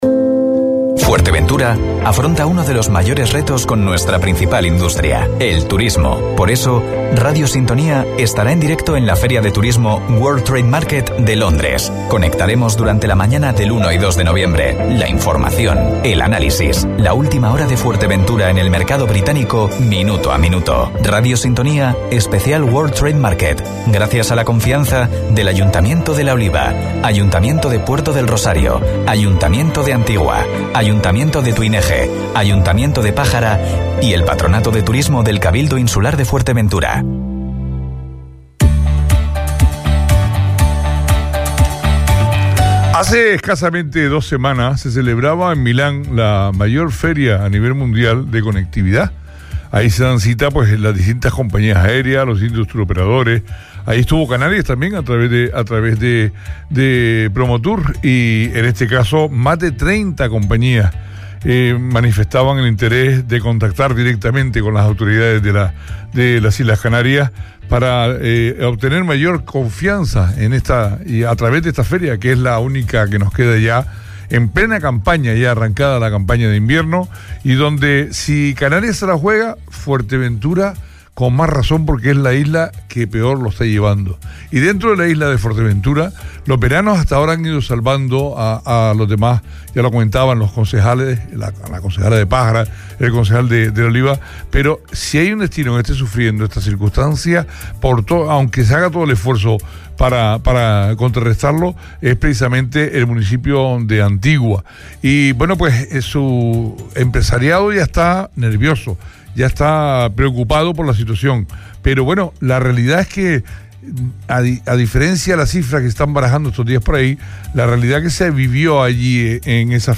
Especial Turismo Feria de Londres, entrevista a Deborah Edgington, concejala de Turismo de Antigua - 29.10.21 - Radio Sintonía
Entrevistas